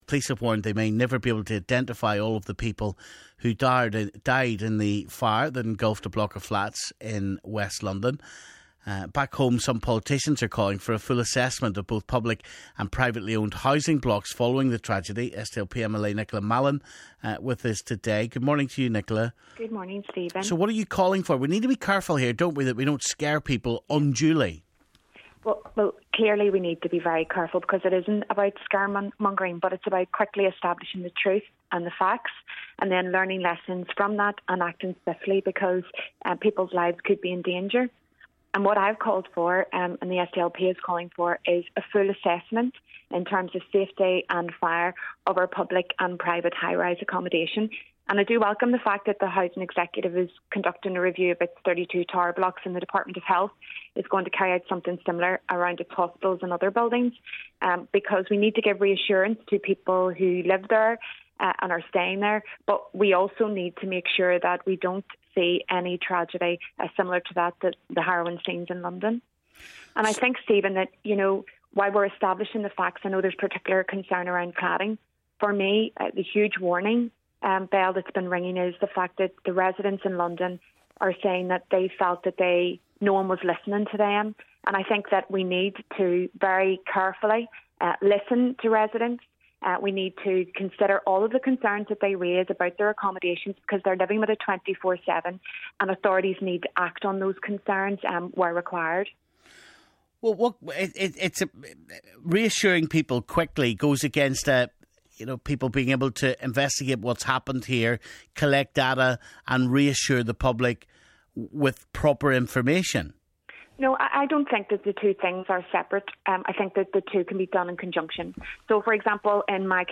Back home and some politicians are calling for a full assessment of both public and privately owned housing blocks following the tragedy. Stephen talks to SDLP MLA Nicola Mallon.